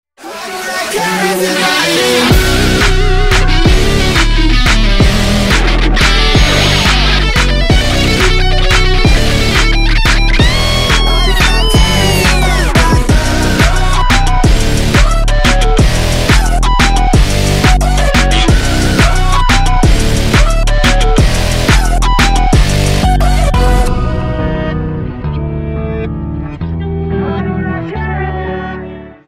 Громкие Рингтоны С Басами
Рингтоны Ремиксы » # Рингтоны Электроника